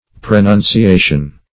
Search Result for " prenunciation" : The Collaborative International Dictionary of English v.0.48: Prenunciation \Pre*nun`ci*a"tion\, n. [L. praenunciatio, fr. praenunciare to announce beforehand.